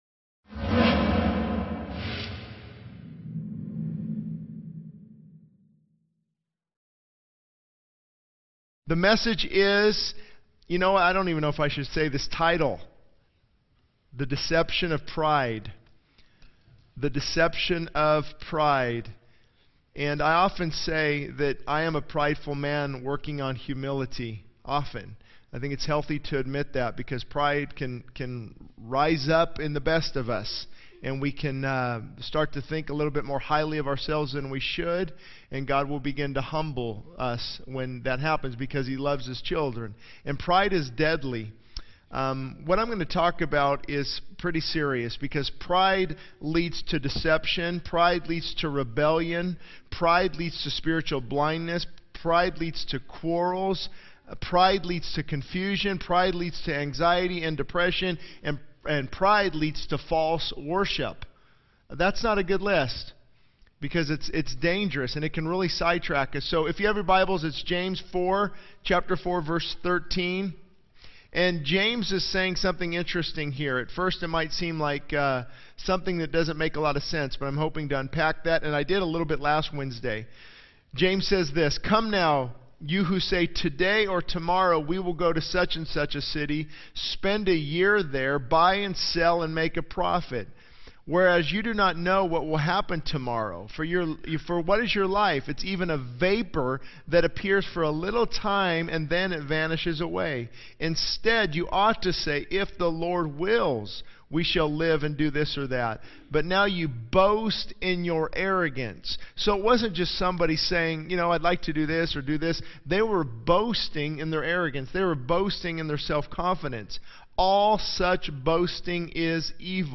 This sermon delves into the dangers of pride, highlighting how it leads to deception, rebellion, spiritual blindness, quarrels, confusion, anxiety, depression, and false worship. The speaker emphasizes the importance of humbling oneself before God, seeking to break the prideful barriers that hinder spiritual growth and true worship.